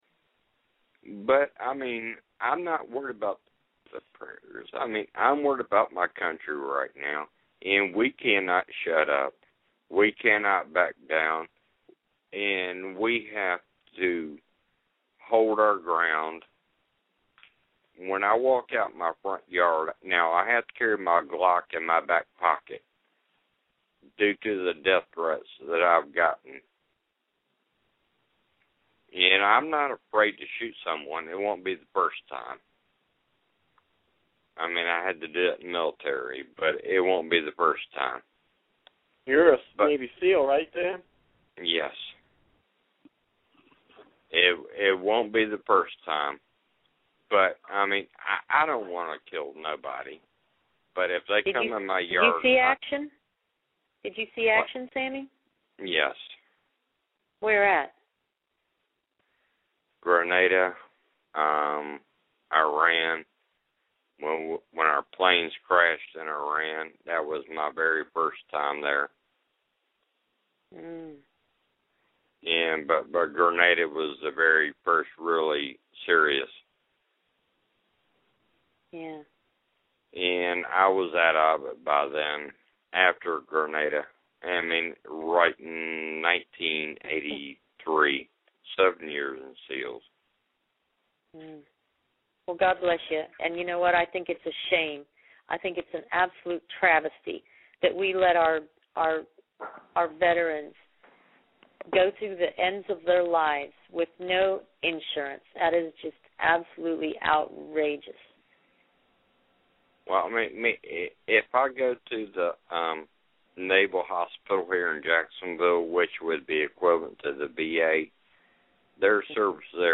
Here is a link to part of a radio show where he makes his SEAL Claims, saying he first saw action in Iran in the early 80′s when his plane crashed.